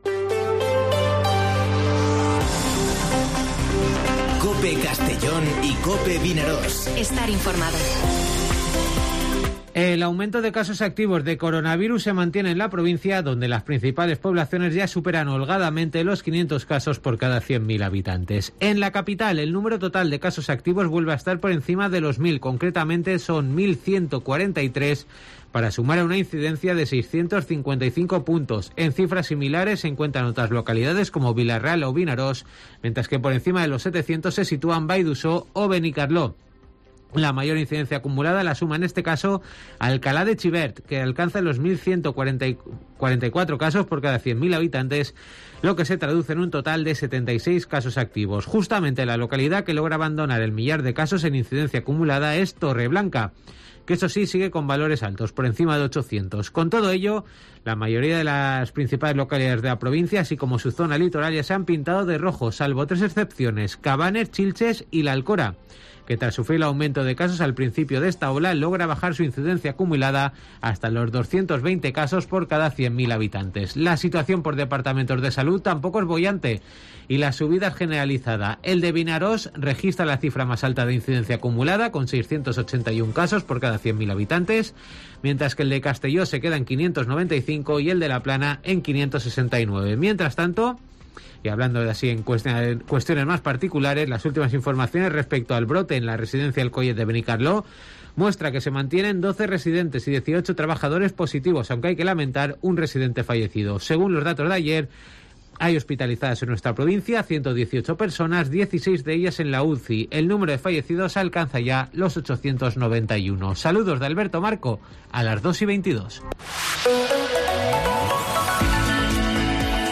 Informativo Mediodía COPE en Castellón (14/12/2021)